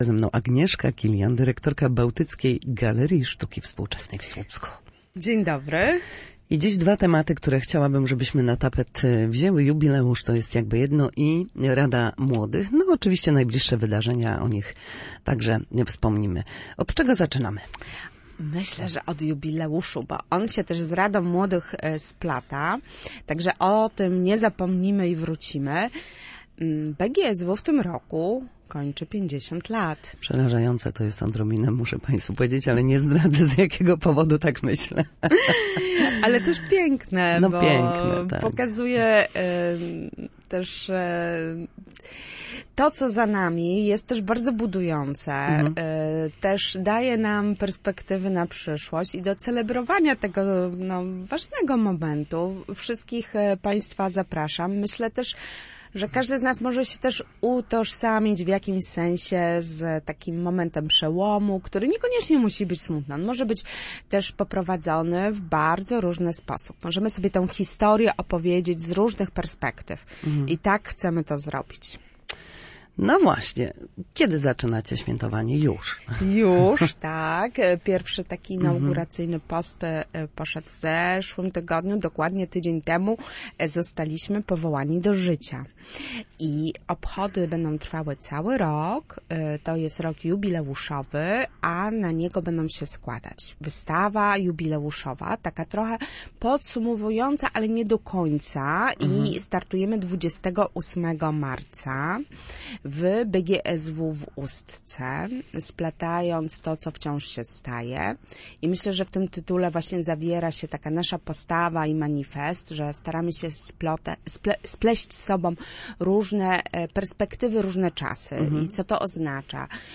Na antenie Studia Słupsk zapraszała na wydarzenia, które w najbliższym czasie odbędą się w obiektach BGSW.